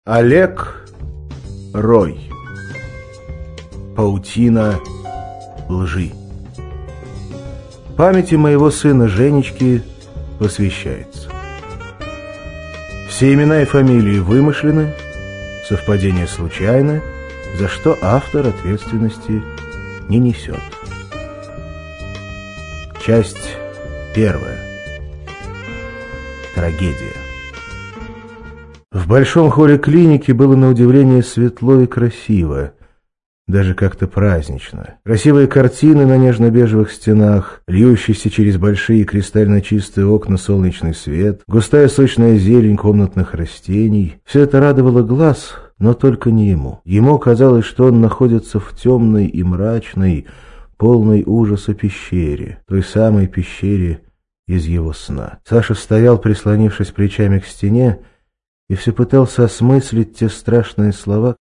Аудиокнига Паутина лжи | Библиотека аудиокниг